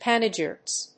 音節pan・e・gyr・ist 発音記号・読み方
/p`ænədʒírɪst(米国英語)/